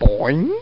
Boooooing! Sound Effect
boooooing.mp3